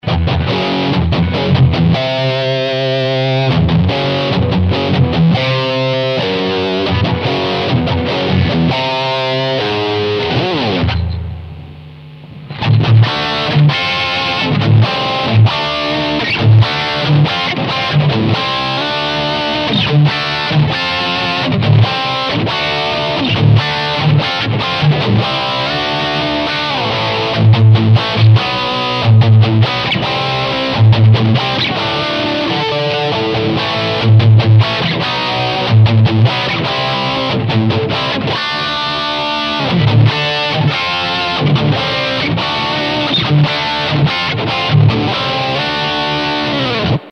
Zu hören sind meine Gold Top (mit Duncan Seth Lover PUs), der Demonizer und eine Prise Hall damit es nicht so knochentrocken ist:
Demonizer - Industrial
Ich habe immer mehr oder weniger dasselbe gespielt, so kann man die Sounds direkt vergleichen, natürlich würde jemand in einer Industrial-Band bestimmt ganz anders spielen, aber ich spiele halt in keiner Industrial-Band. :-)